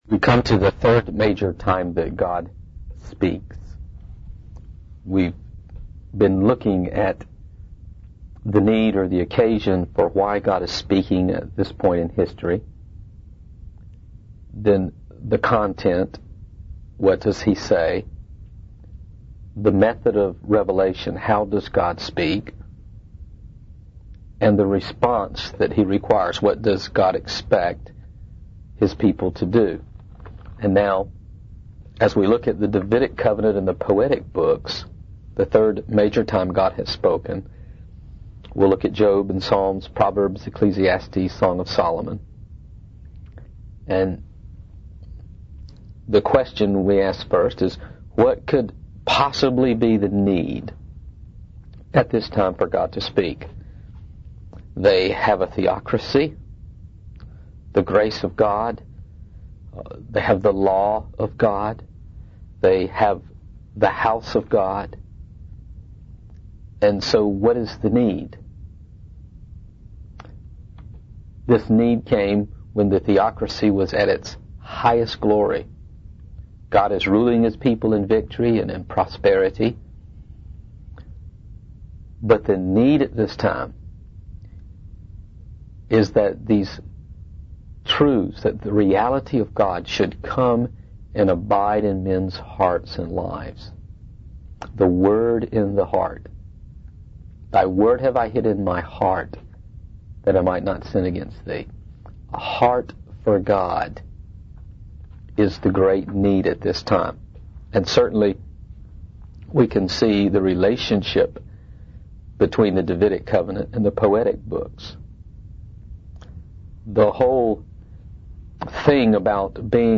In this sermon, the preacher discusses the story of Job and how he went from prosperity to poverty, losing everything.